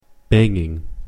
/ˈbæŋ.ɪŋ(英国英語), ˈbæŋɪŋ(米国英語)/